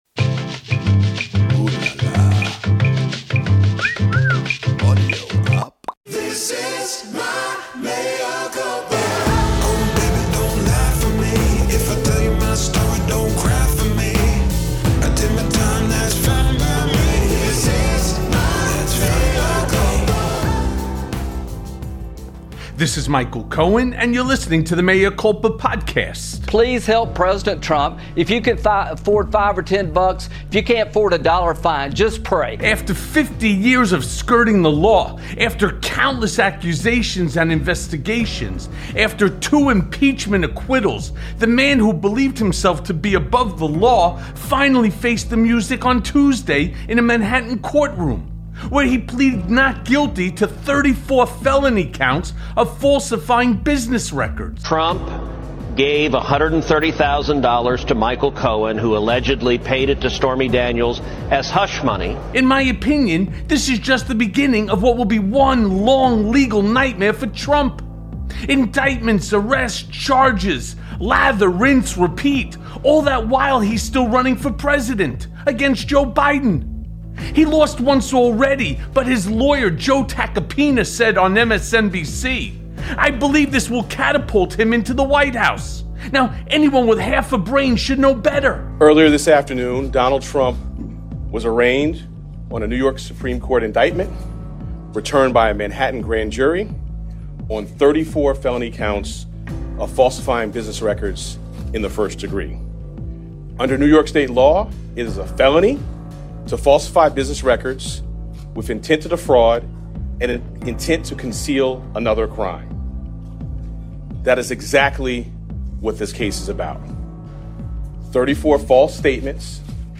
Trump Targets Judge’s Daughter in Dangerous Attack + A Conversation with Norm Eisen